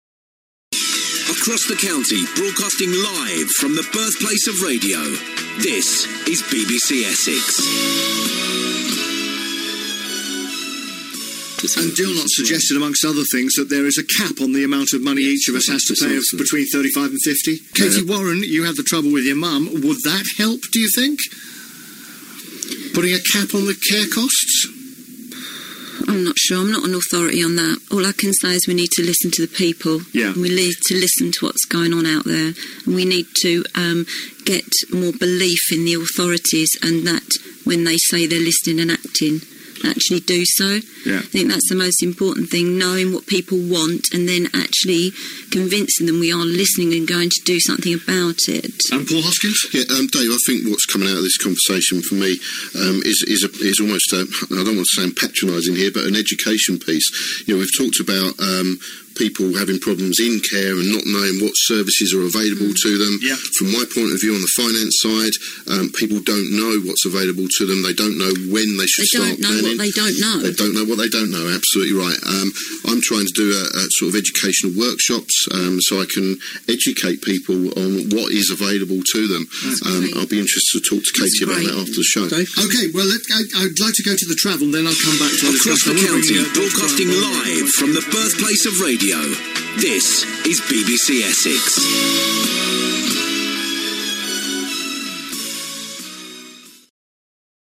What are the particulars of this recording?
talking live